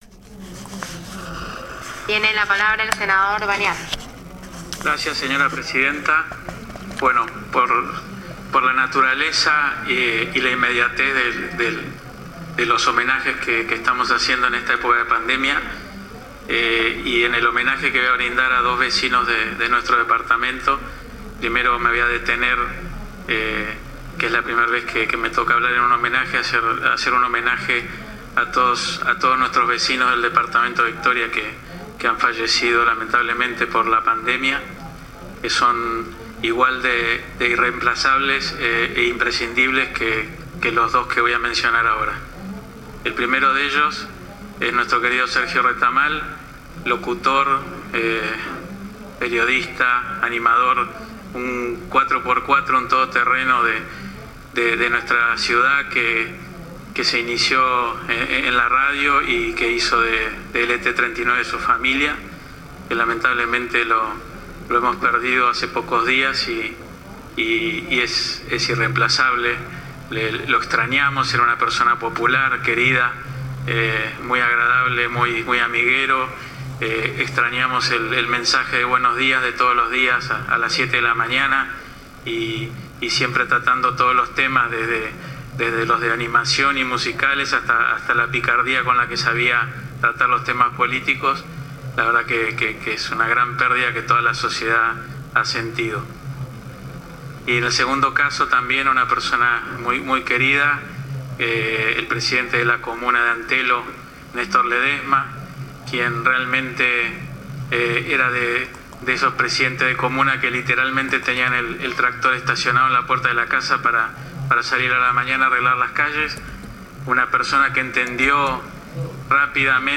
Senador Gastón Bagnat
Homenaje-Gaston-Bagnat.mp3